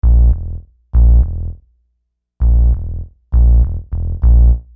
Bass 12.wav